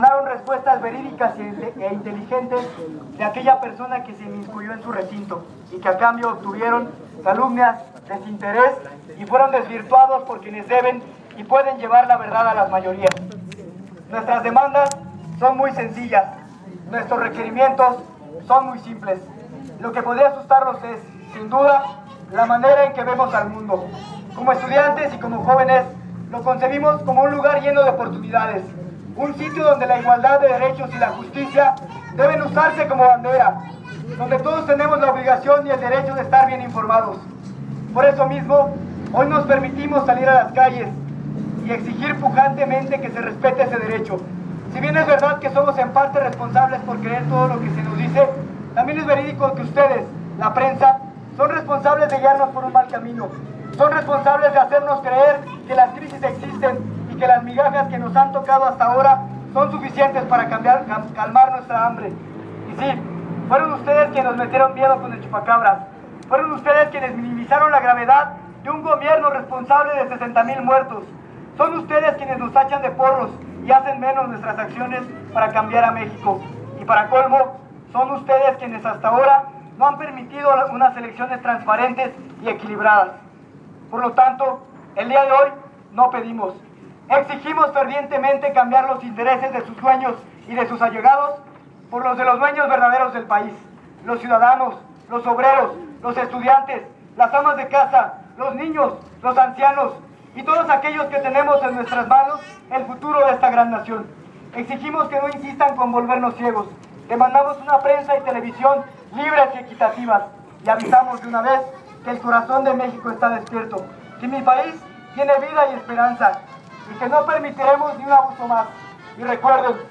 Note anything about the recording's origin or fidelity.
Termina marcha con discurso en Constituyentes